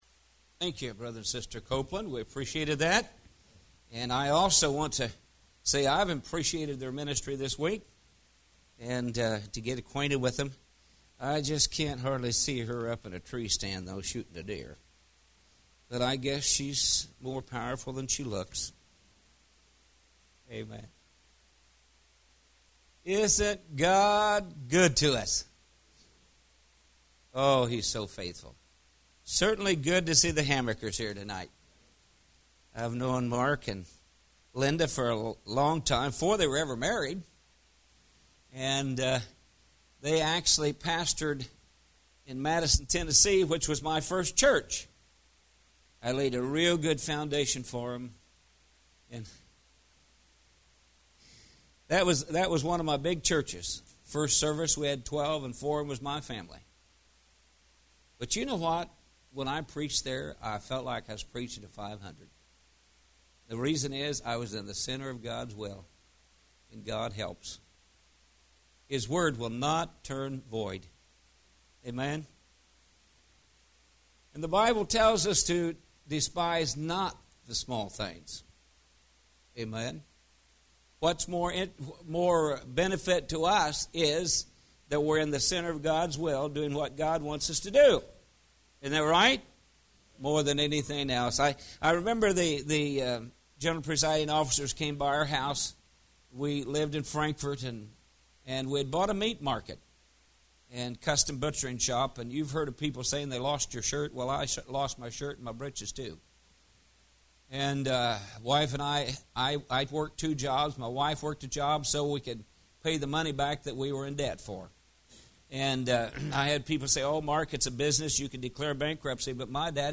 Series: Spring Revival 2017